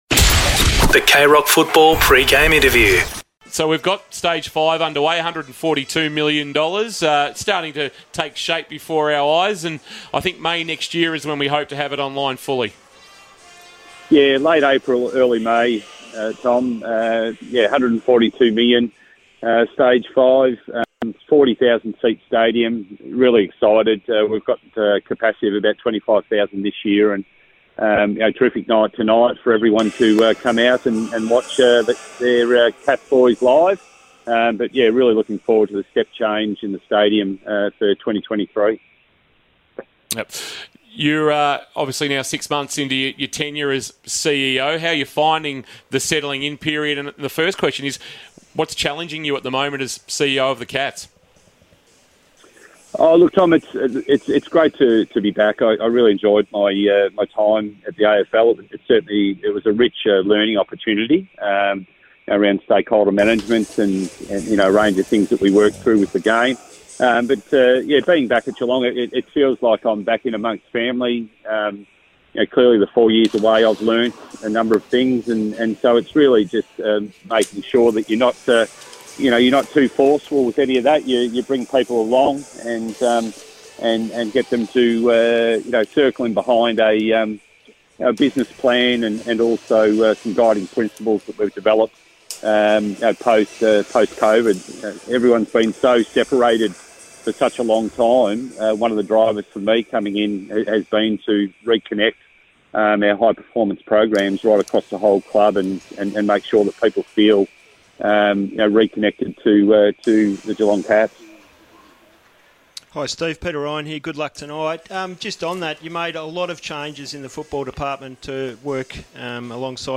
2022 - AFL - ROUND 4 - GEELONG vs. BRISBANE LIONS: Pre-match Interview